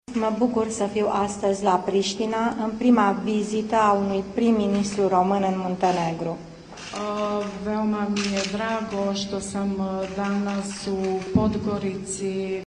Aflată în vizită oficială în Muntenegru, la Podgorica, şefa guvernului de la Bucureşti a susţinut în discursul său că se află la Pristina, capitala Kosovo.
Premierul a spus, în cadrul conferinței comune cu omologul muntenegrean, că îi pare bine să fie la Pristina, care este de fapt capitala Kosovo, un stat nerecunoscut de România. Translatorul oficial a corectat eroarea.
25iul-21-dancila-spune-pristina-traducerea.mp3